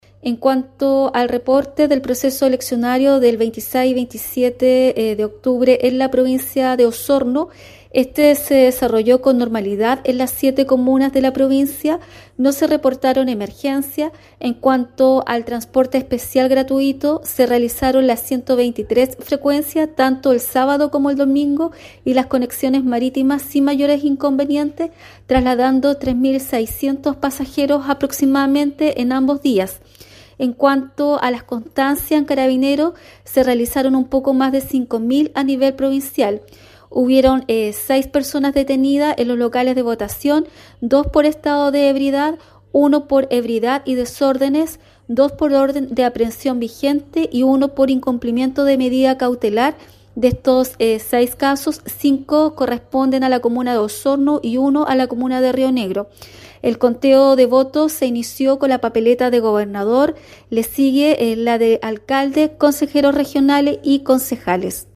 Así lo explicó en su informe, la Delegada Presidencial Provincial, Claudia Pailalef